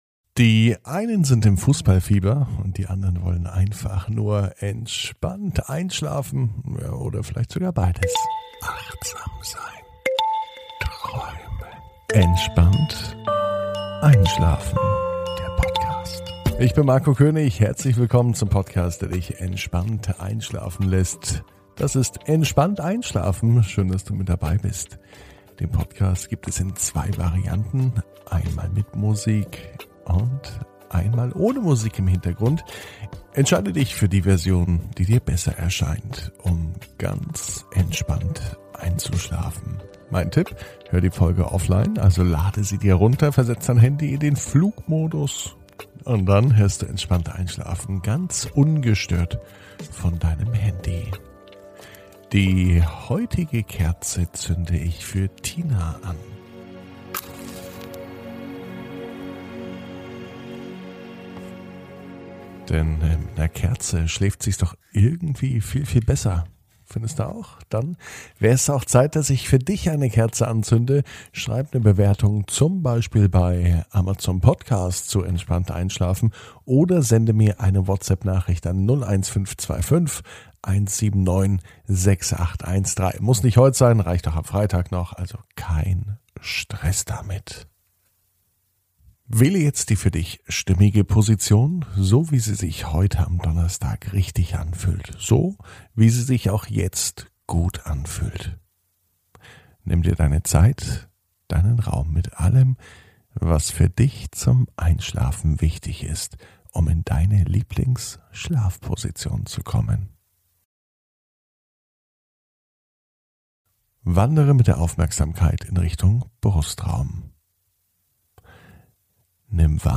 (ohne Musik) Entspannt einschlafen am Donnerstag, 17.06.21 ~ Entspannt einschlafen - Meditation & Achtsamkeit für die Nacht Podcast